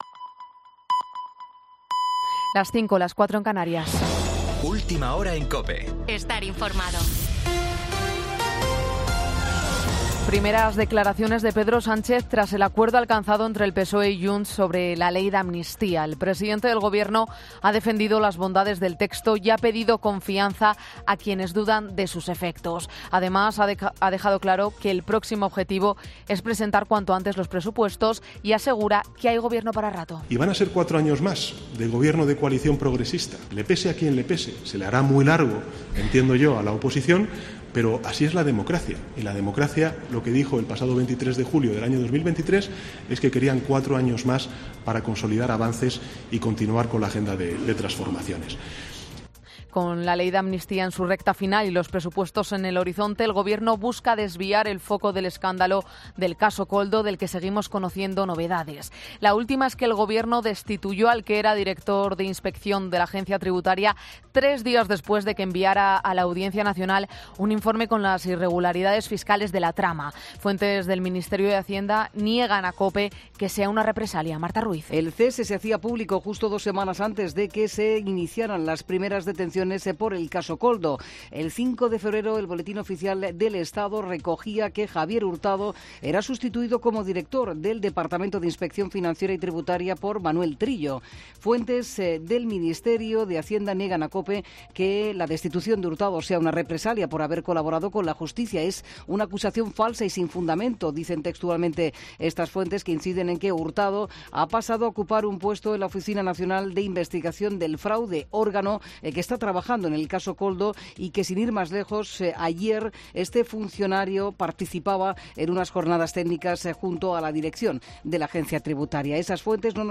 Boletín 05.00 horas del 9 de marzo de 2024